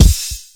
Shady_Kick_4.wav